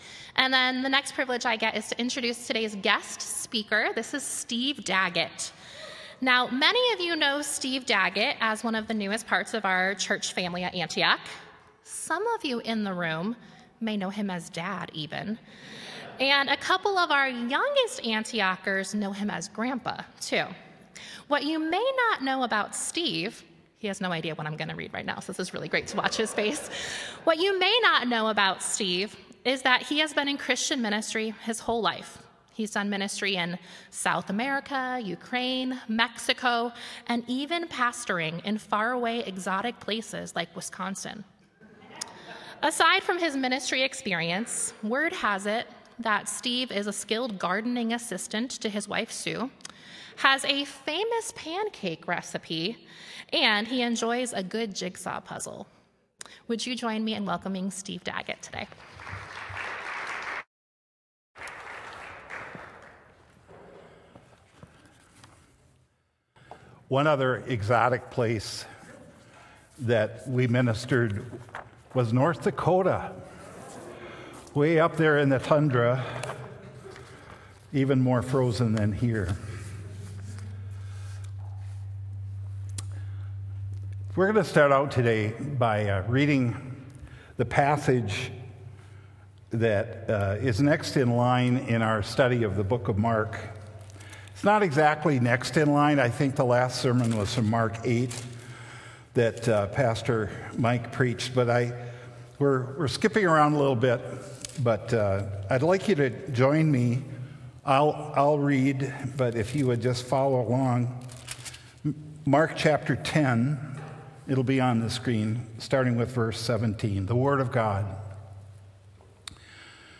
Sermon: Mark: The Upside Down Kingdom
sermon-mark-the-upside-down-kingdom.m4a